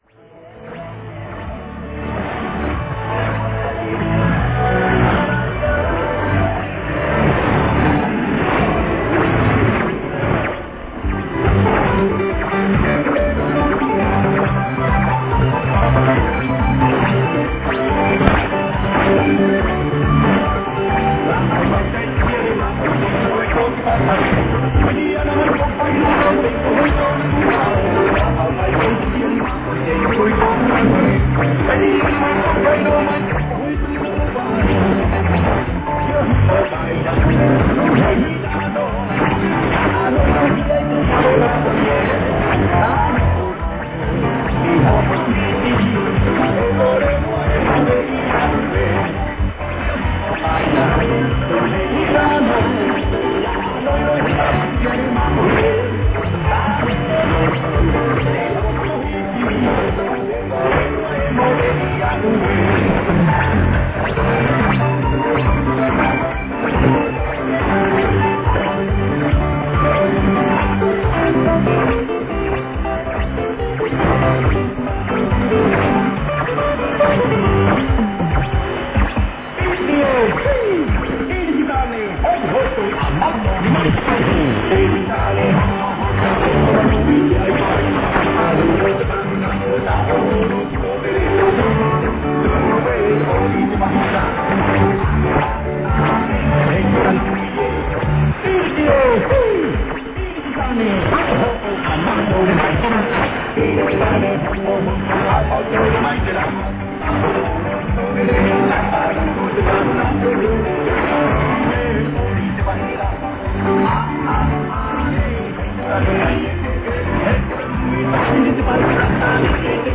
ノリノリの南洋音楽を聴きながら仰向けで大の字になって空を眺めておりました。
<受信地：東京都北区赤羽 荒川河川敷 RX:ICF-SW7600GR ANT:AN-12>
※03:39-03:42 女性「Radio Vanuatu Vois Blong Yumi」